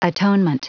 Prononciation du mot atonement en anglais (fichier audio)